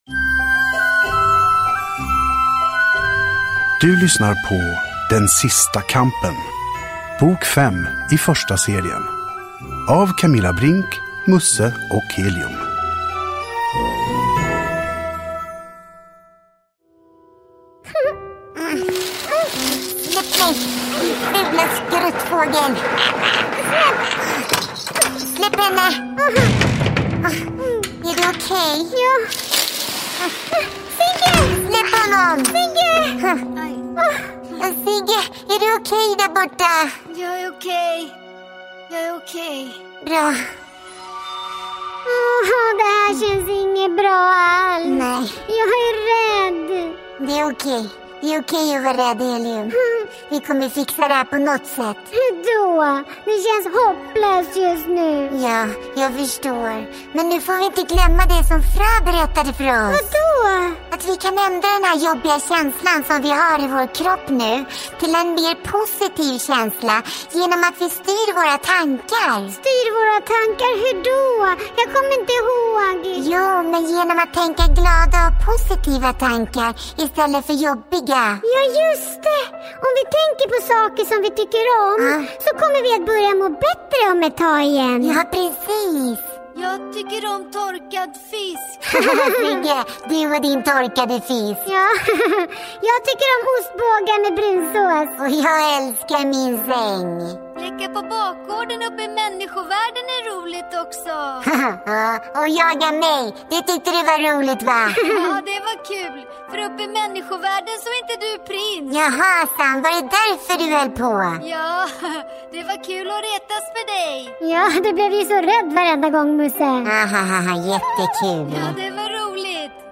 Dramatiserad ljudberättelse!
Uppläsare: Camilla Brinck